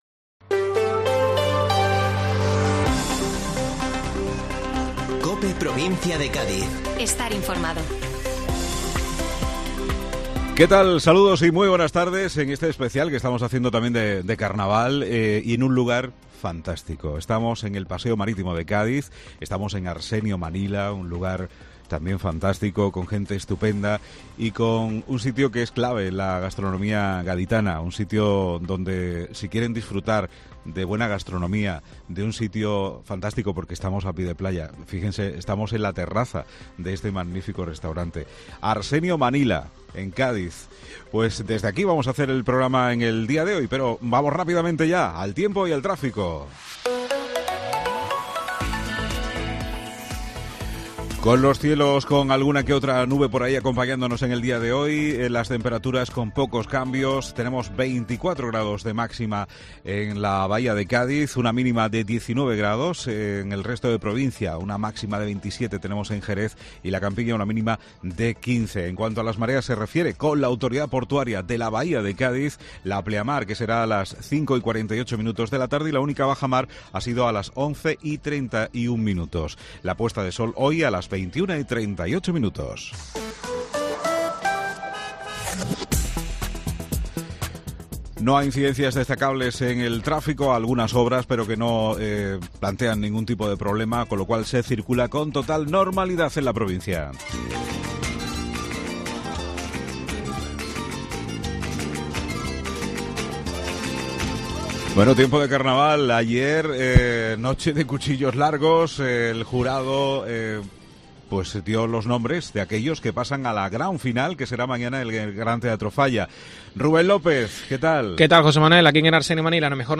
La Cadena COPE en el resturante Arsenio Manila